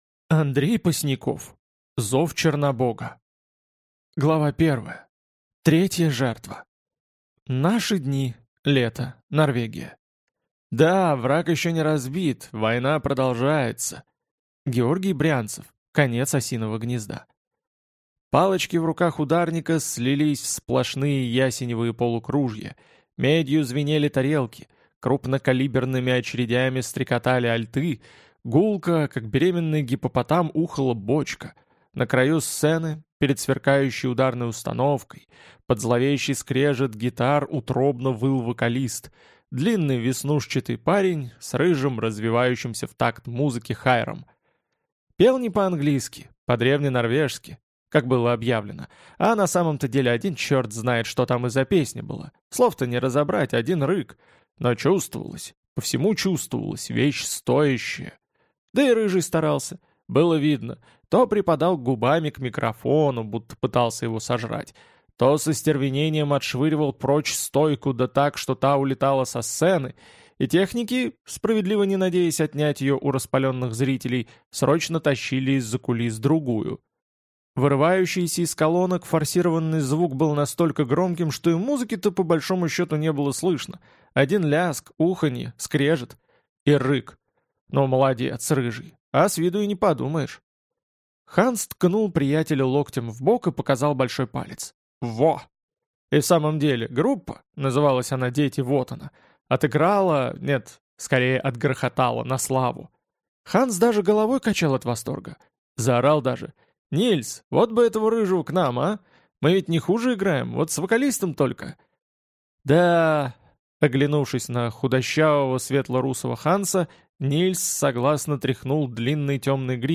Аудиокнига Зов Чернобога | Библиотека аудиокниг